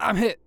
Barklines Combat VA